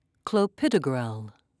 (clo-pi'do-grel)